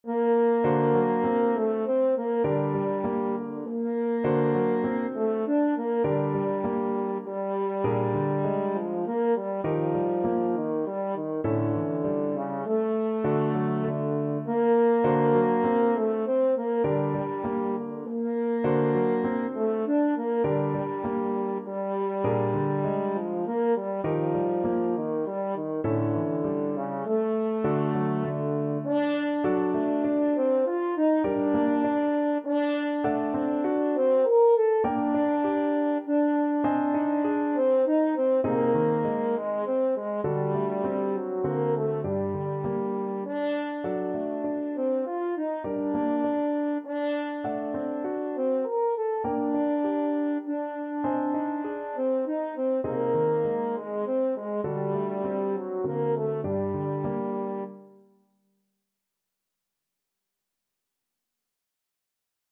French Horn
G minor (Sounding Pitch) D minor (French Horn in F) (View more G minor Music for French Horn )
3/4 (View more 3/4 Music)
Etwas bewegt
Classical (View more Classical French Horn Music)